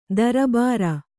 ♪ darabāra